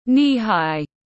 Cao đến đầu gối tiếng anh gọi là knee-high, phiên âm tiếng anh đọc là /ˌniːˈhaɪ/ .
Knee-high /ˌniːˈhaɪ/